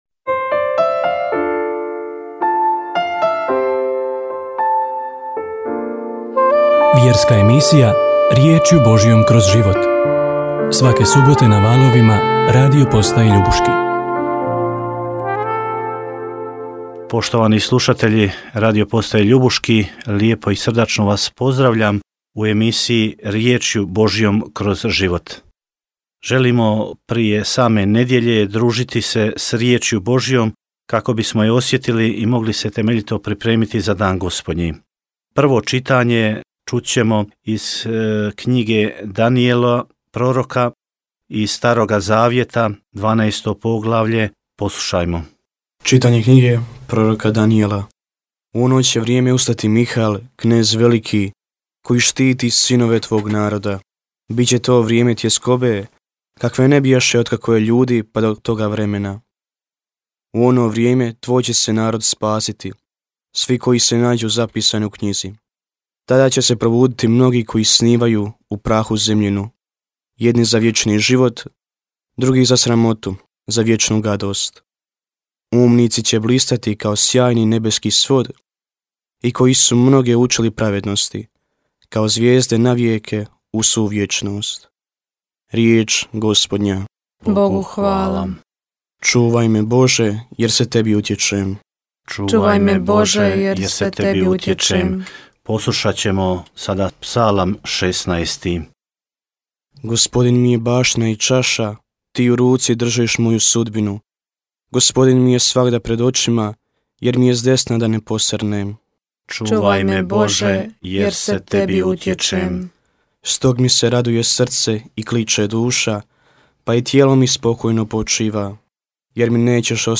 Na početku emisije prvo smo čuli nedjeljna čitanja, gdje se između ostalog govorilo i o razorenju Jeruzalema.